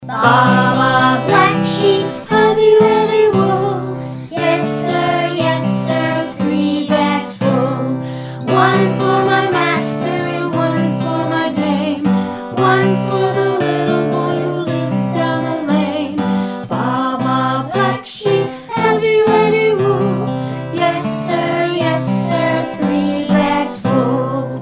Vocal Song Downloads